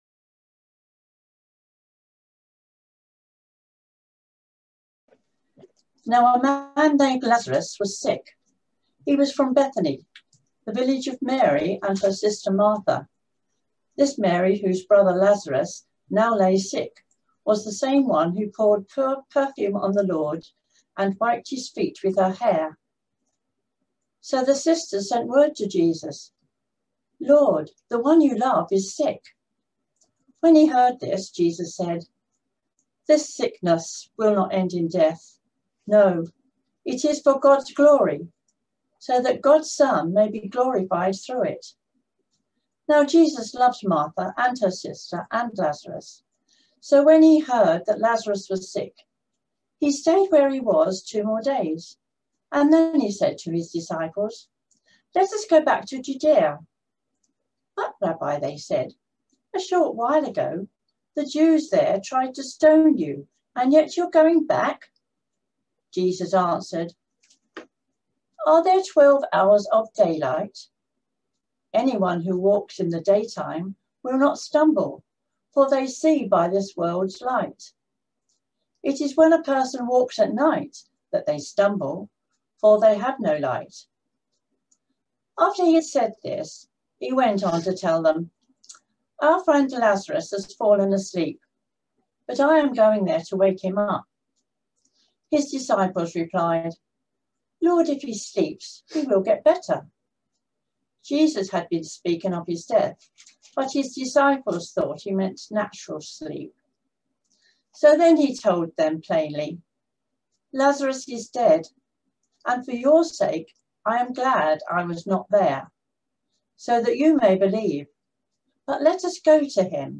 A sermon preached on 21st March, 2021, as part of our John series.